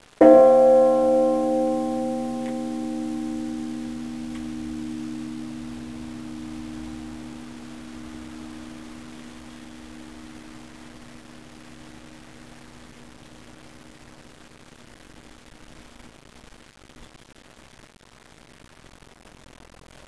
Die folgenden Beispiele gehören zum Geläut der 1641 erbauten Holzkirche in Clausthal.
Abb. 03: Clausthaler Marktkirche, große Glocke, Bronze 1792,